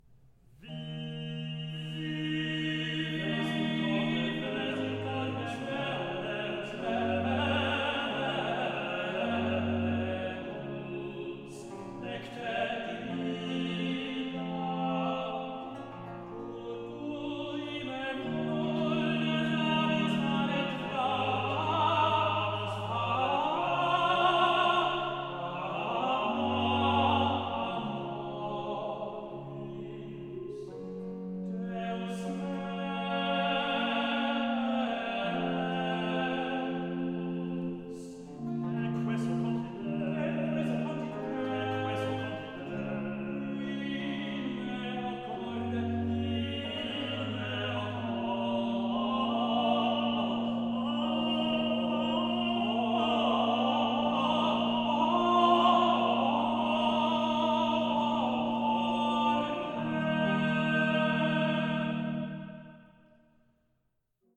soprano
tenors
organ
sounding revelatory as choral works in sacred guise.